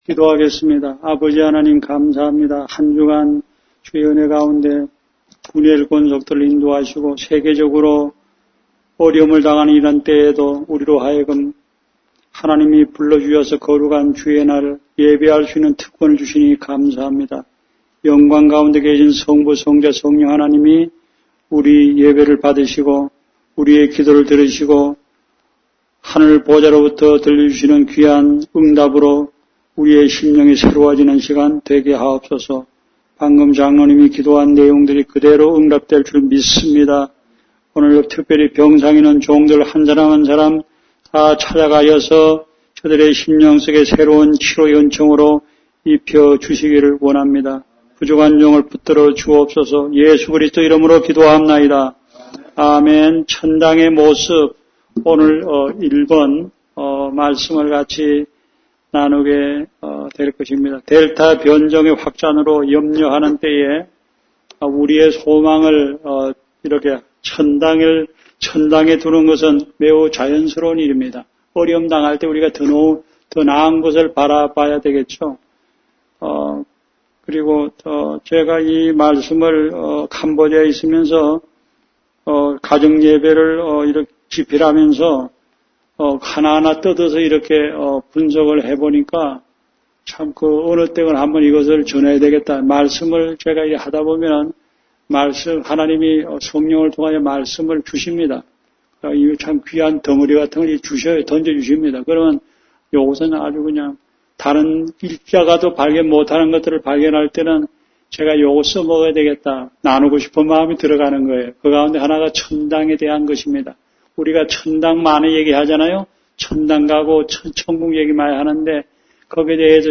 Series: 주일설교